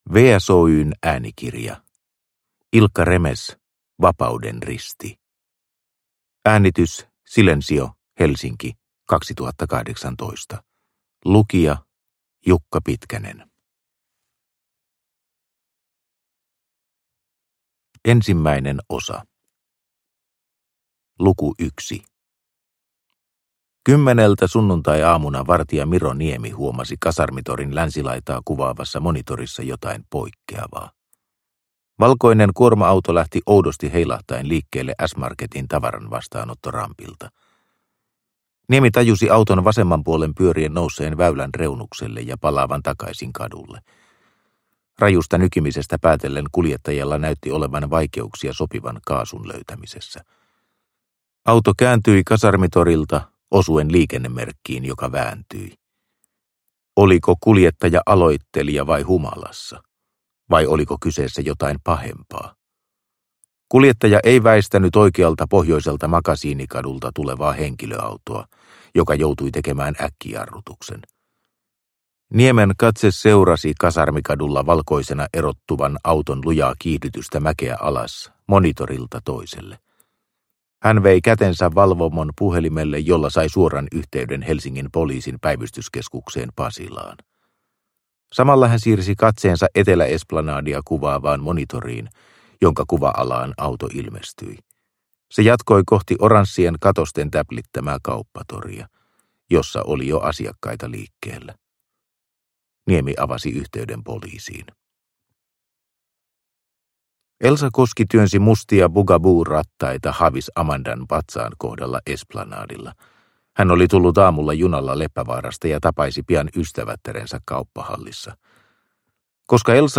Vapauden risti – Ljudbok – Laddas ner